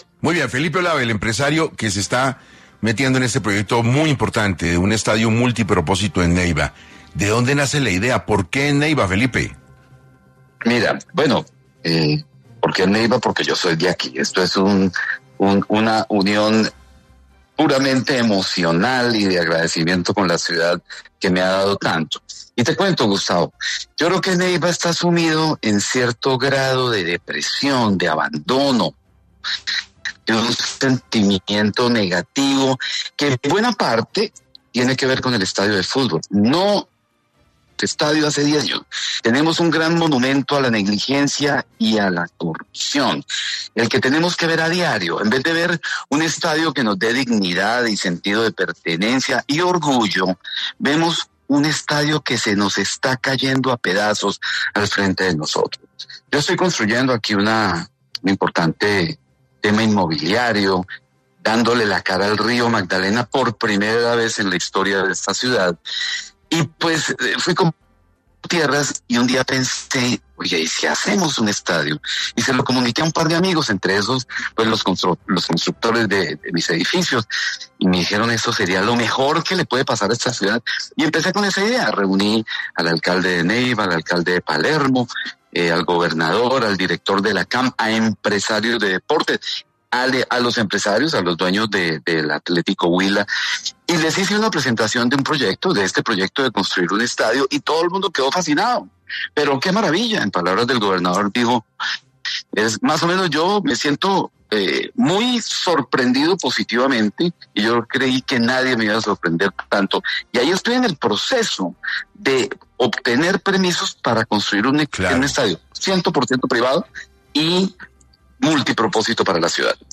empresario colombiano, habló en 6 AM de Caracol Radio sobre el proyecto de un estadio multipropósito en Neiva